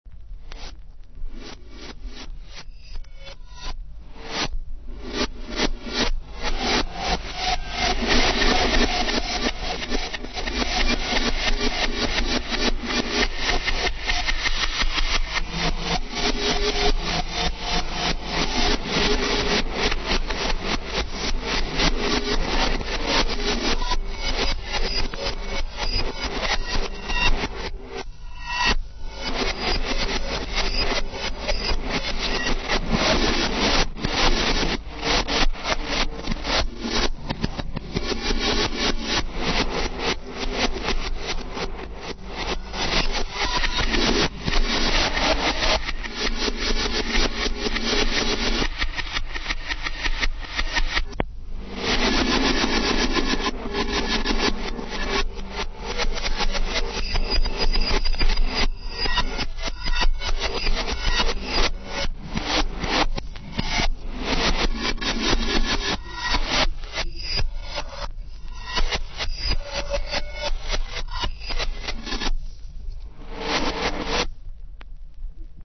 33 tours mono